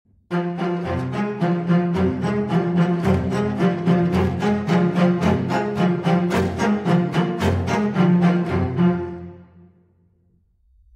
Scary Violin - Botón de Efecto Sonoro